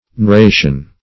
Search Result for " neuration" : The Collaborative International Dictionary of English v.0.48: Neuration \Neu*ra"tion\, n. (Biol.) The arrangement or distribution of nerves, as in the leaves of a plant or the wings of an insect; nervation; venation.